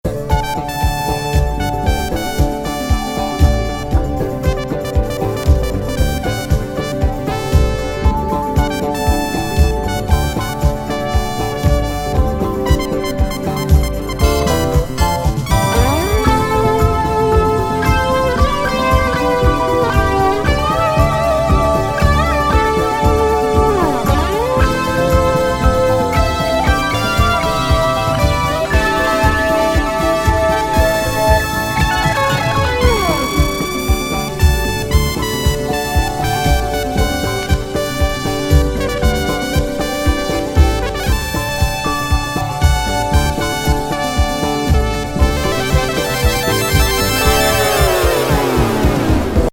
スムース・ディスコにオーバーダブ。